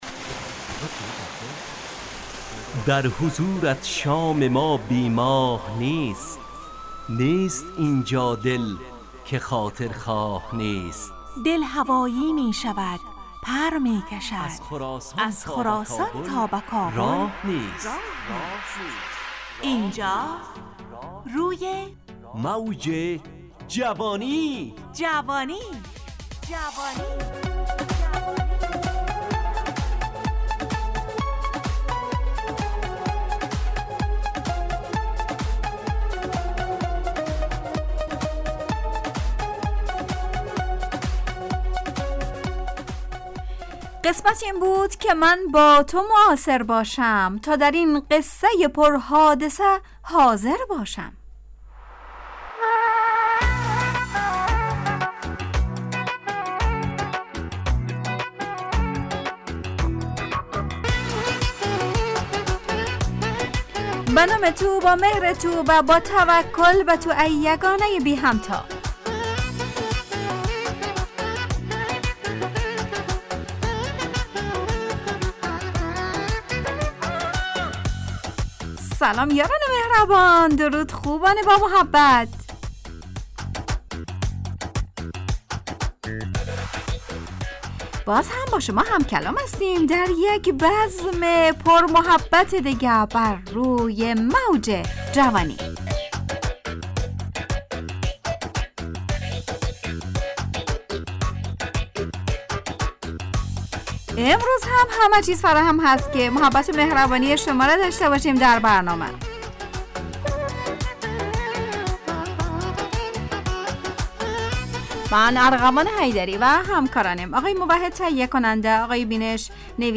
روی موج جوانی، برنامه شادو عصرانه رادیودری.
همراه با ترانه و موسیقی مدت برنامه 55 دقیقه . بحث محوری این هفته (مهربانی) تهیه کننده